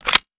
weapon_foley_drop_17.wav